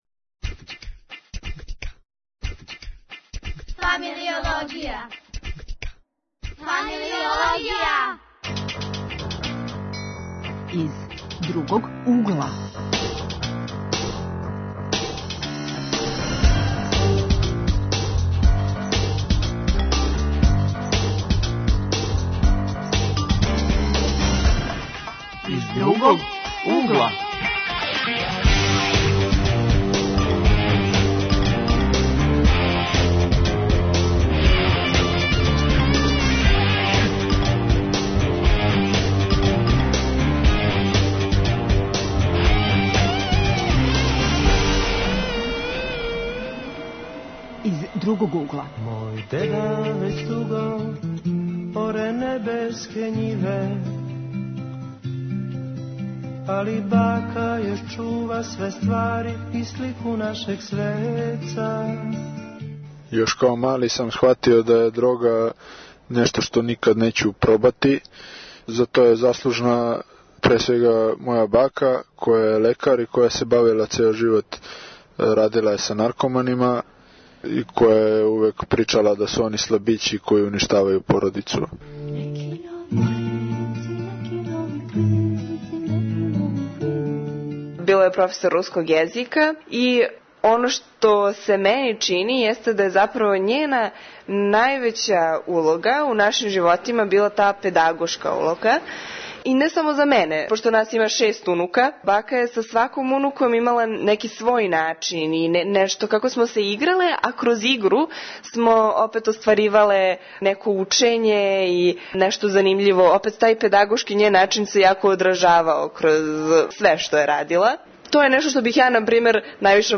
Гости су студенти; Глас савести - биће глас психолога; АШ-ће се бавити занимљивостима о васпитању;У Зони културе упознајемо младе уметнике.